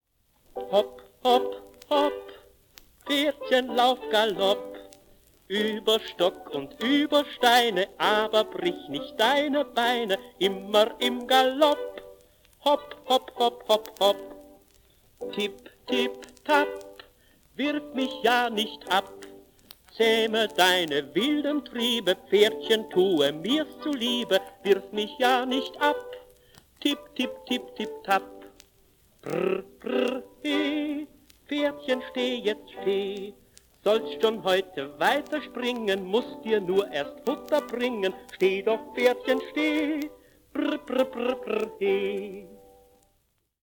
ist ein deutschsprachiges Kinderlied